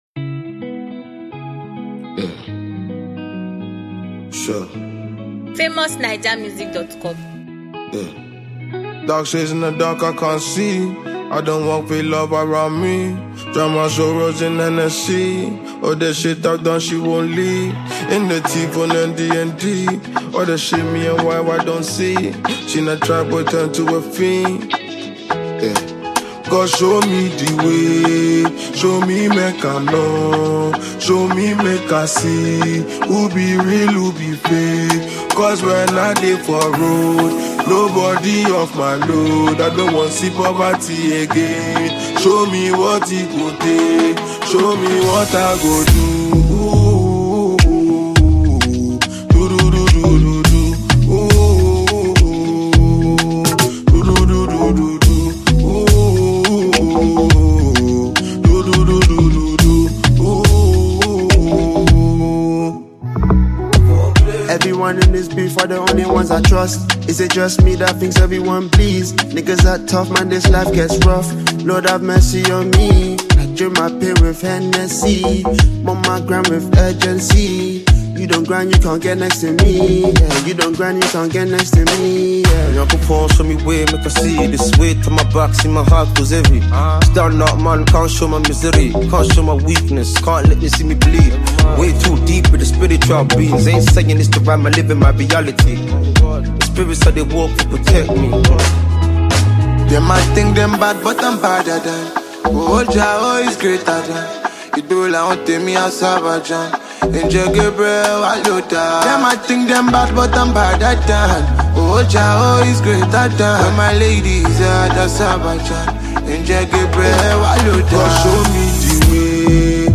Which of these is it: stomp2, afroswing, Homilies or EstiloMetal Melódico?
afroswing